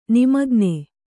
♪ nimagne